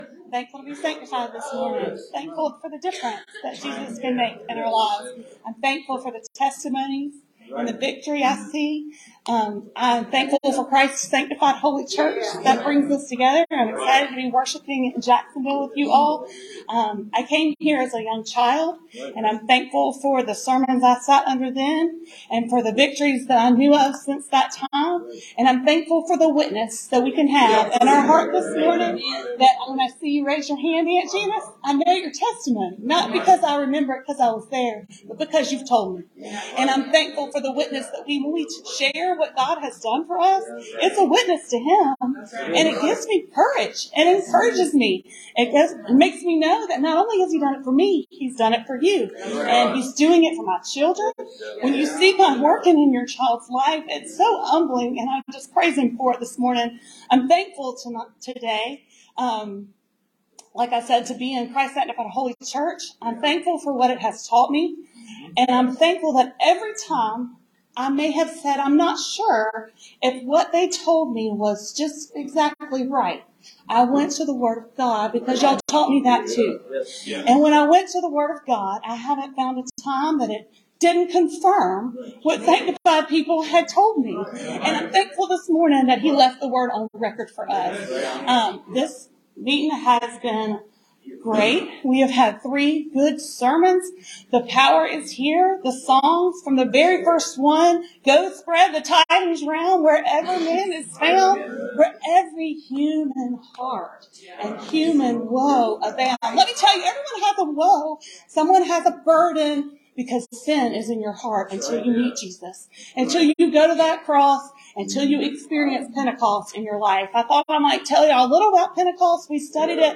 In advance of Pentecost Sunday, I recommend that you listen to this powerful sermon.